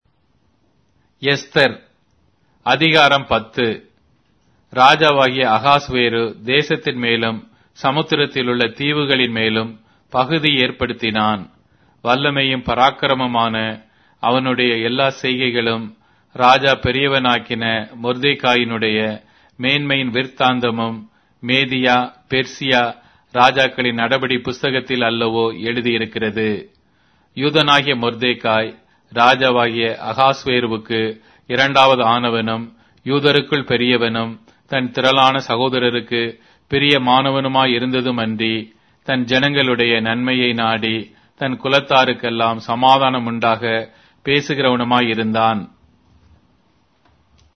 Tamil Audio Bible - Esther 1 in Urv bible version